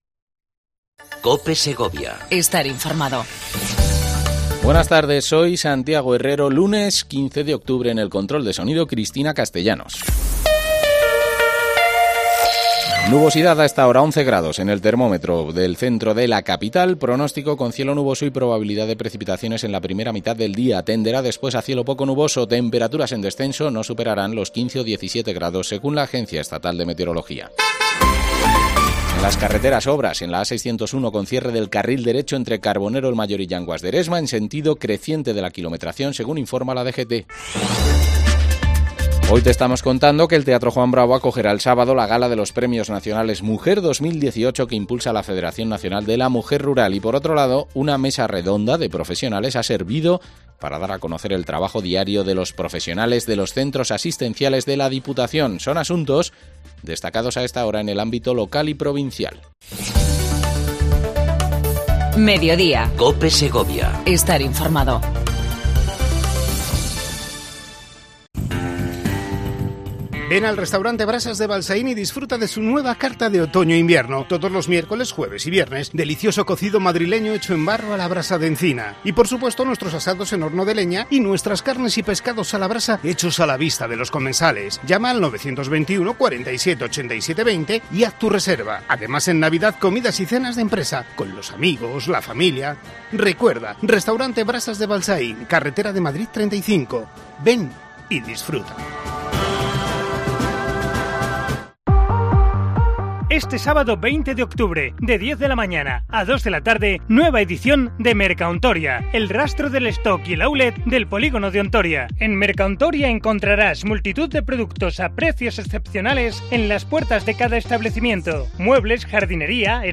AUDIO: Entrevista al delegado territorial de la Junta de Castilla y León en la provincia, Javier López Escobar